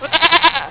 Goats
GOATS.wav